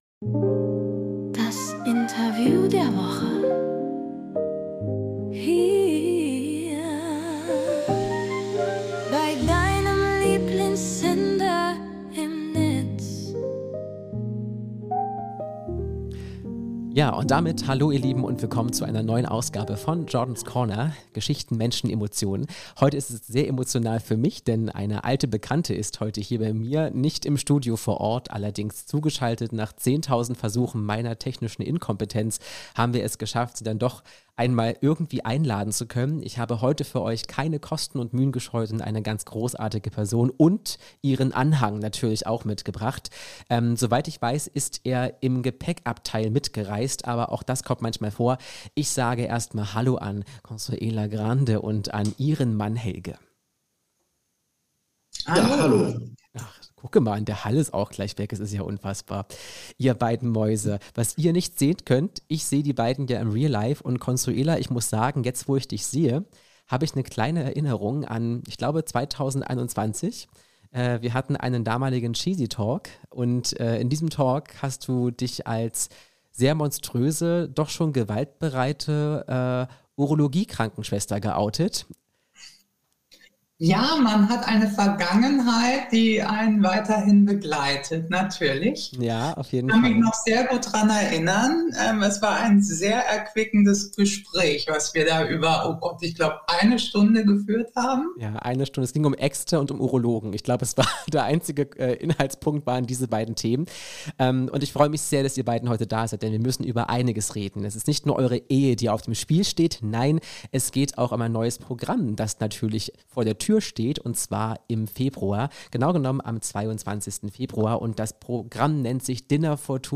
Interview der Woche